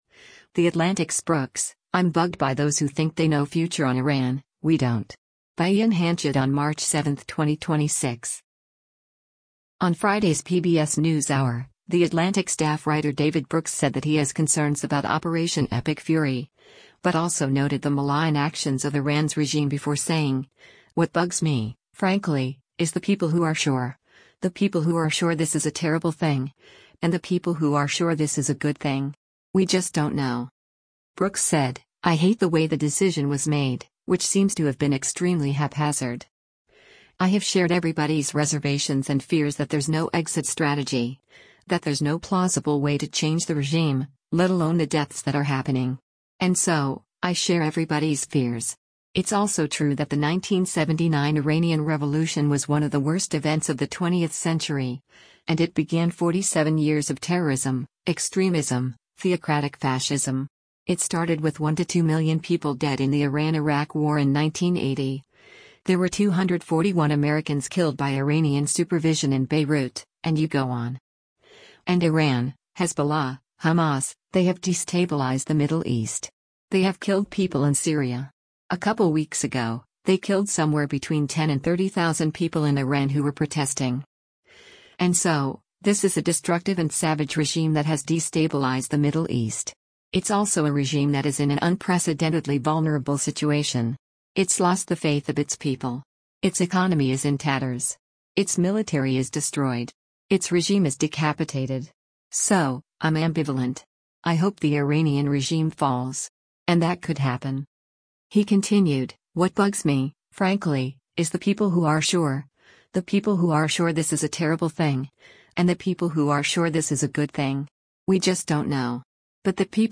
On Friday’s “PBS NewsHour,” The Atlantic Staff Writer David Brooks said that he has concerns about Operation Epic Fury, but also noted the malign actions of Iran’s regime before saying, “What bugs me, frankly, is the people who are sure, the people who are sure this is a terrible thing, and the people who are sure this is a good thing. We just don’t know.”